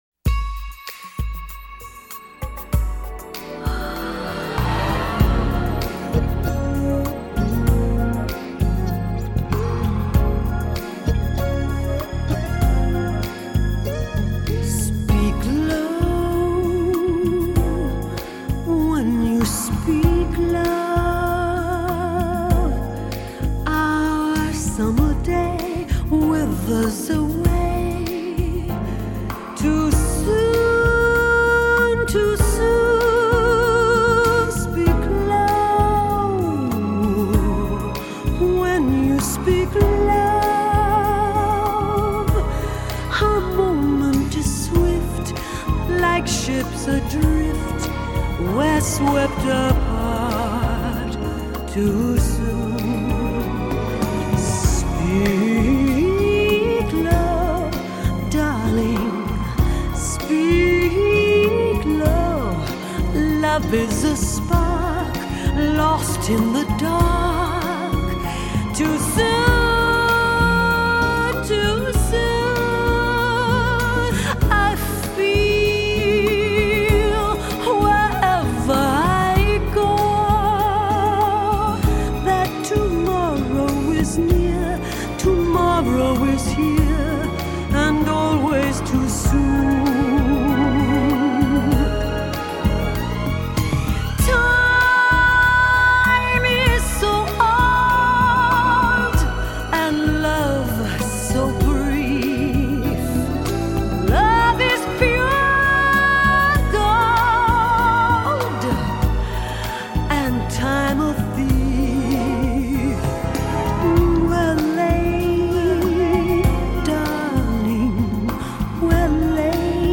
smooth jazz radio station vibe
but I do like the way she caresses the melody.